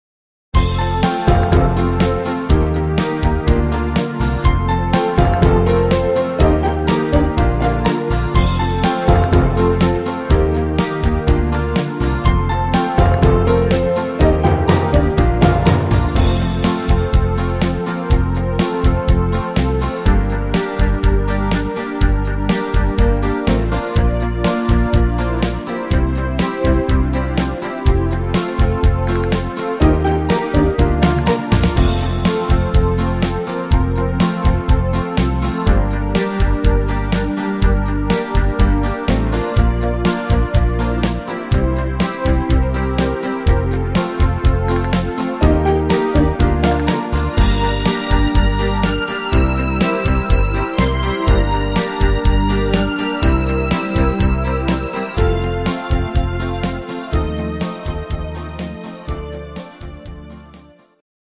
Rhythmus  Beat Fox
Art  Schlager 90er, Deutsch